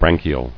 [bran·chi·al]